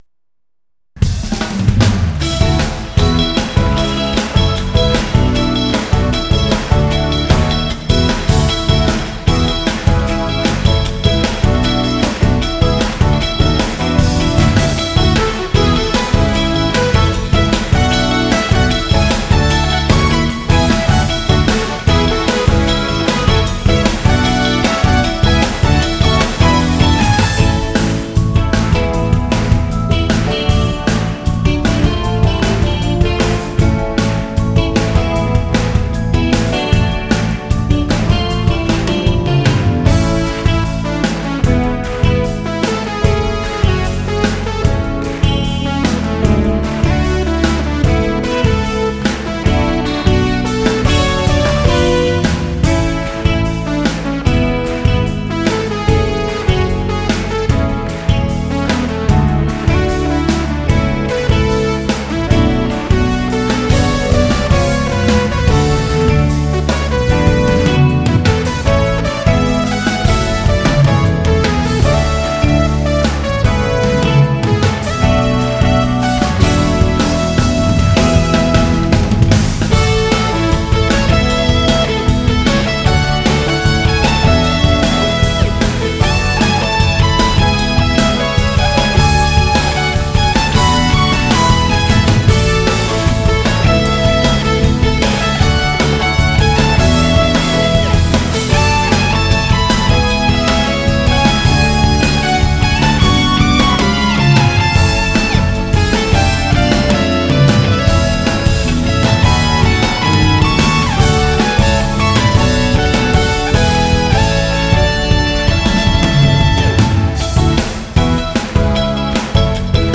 하지만 분위기와 사운드는 쵝오~ㅠㅠd